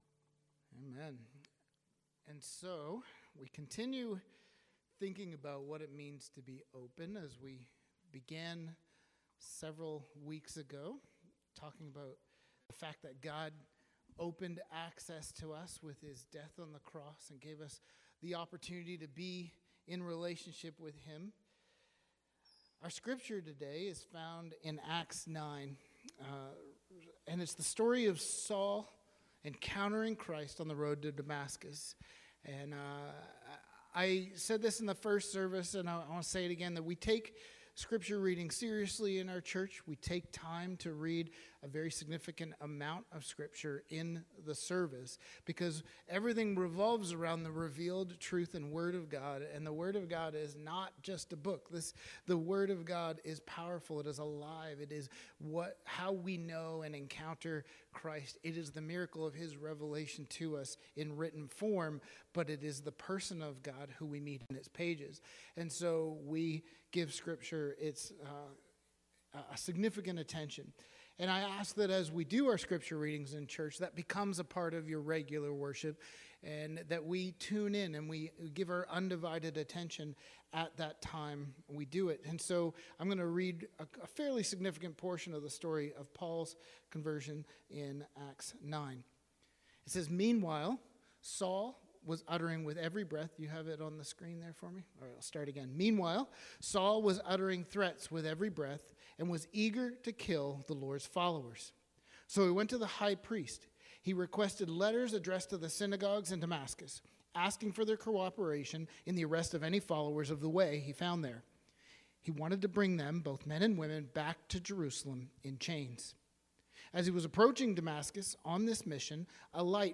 Sermons | Sunnyside Wesleyan Church